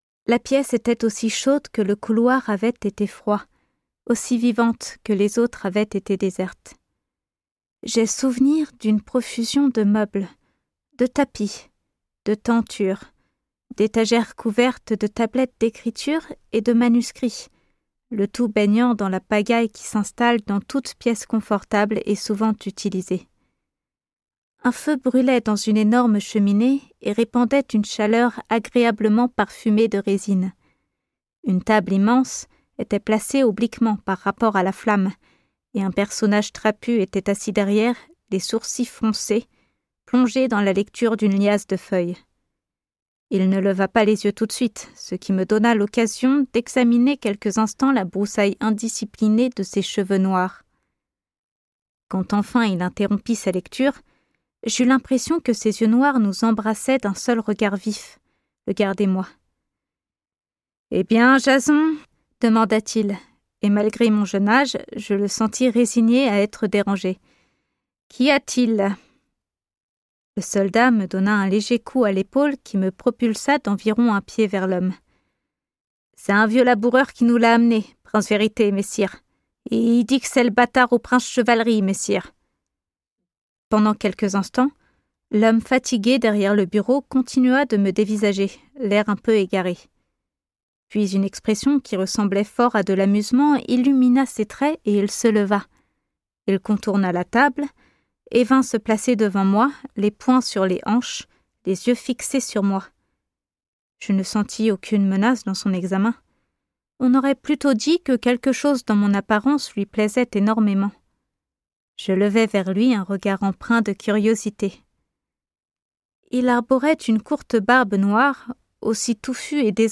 Narration - imaginaire
5 - 30 ans - Soprano